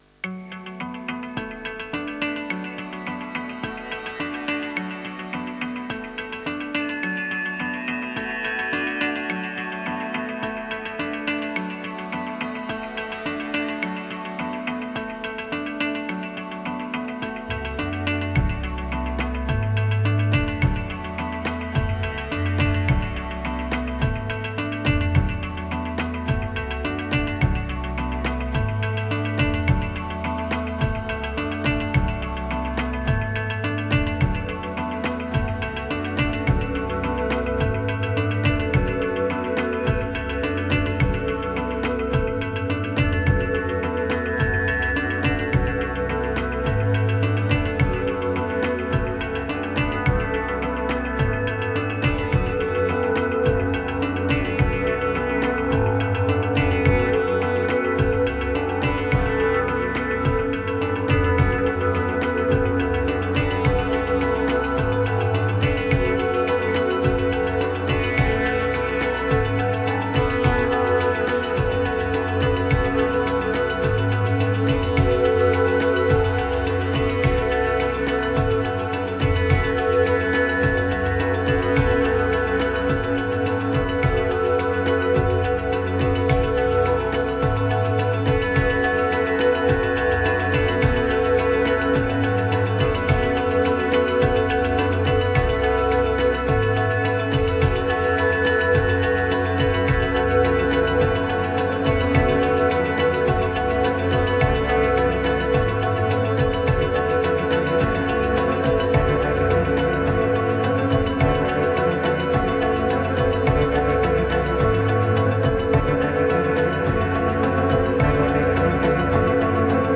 2127📈 - 82%🤔 - 53BPM🔊 - 2023-10-15📅 - 379🌟
Mid-day mix and mastering.